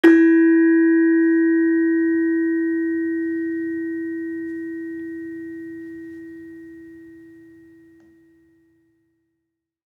Saron-3-E3-f.wav